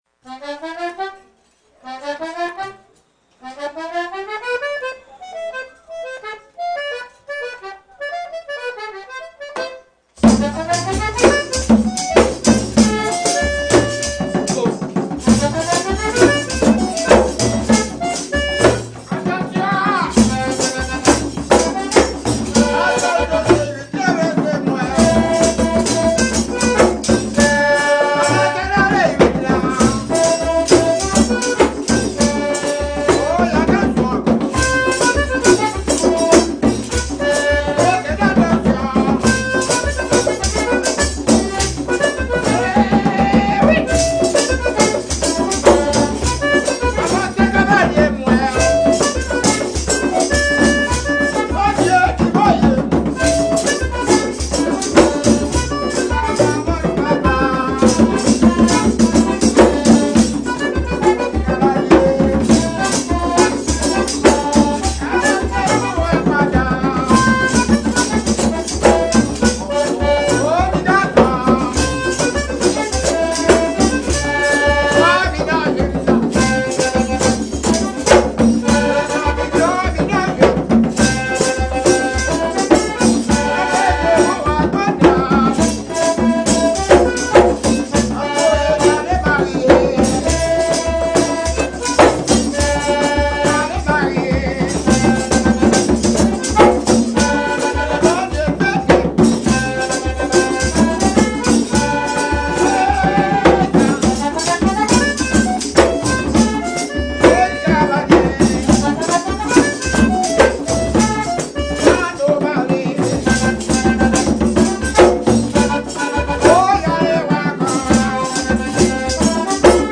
FLOKLORE HAITIEN audio closed https